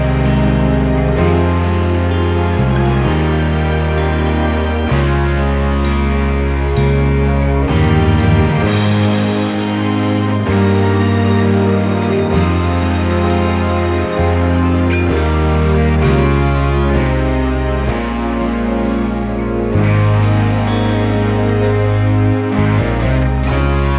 no Backing Vocals Country (Female) 3:36 Buy £1.50